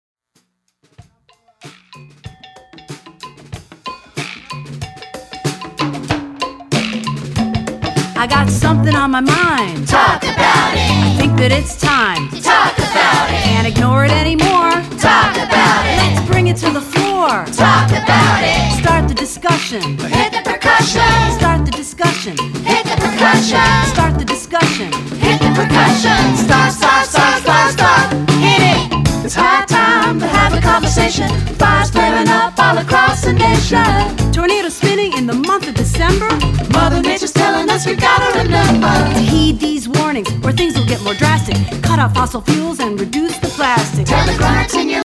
rock songs